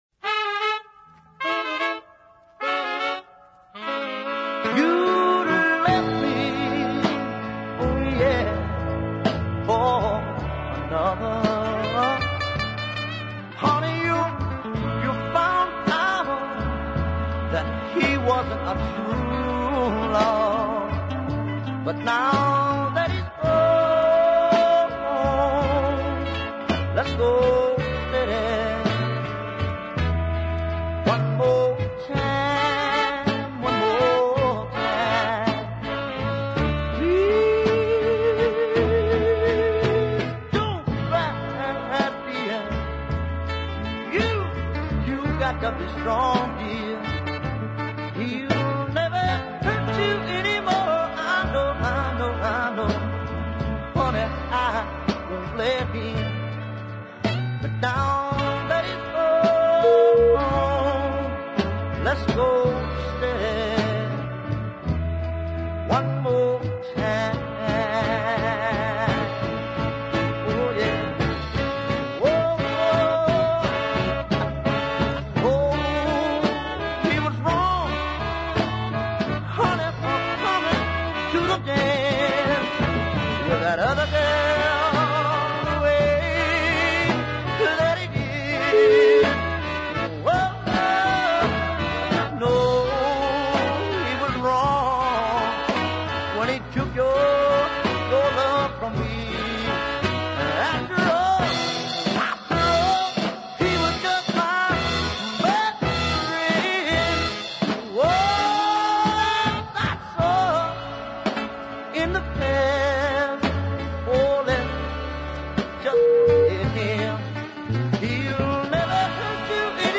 Southern Soul setting
Within a fine arrangement of rhythm and horns
gospel drenched vocal